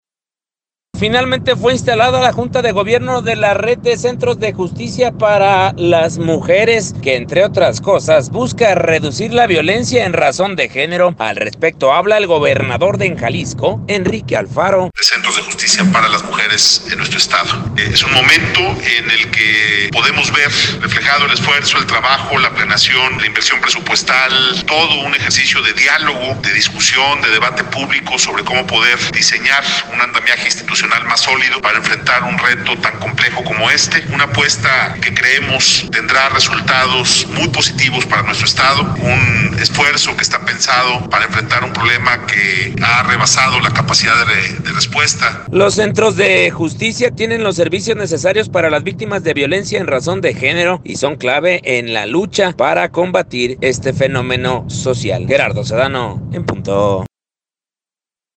Finalmente fue instalada la junta de gobierno de la Red de Centros de Justicia para las Mujeres, que entre otras cosas, busca reducir la violencia en razón de género. Al respecto habla, el gobernador de Jalisco, Enrique Alfaro: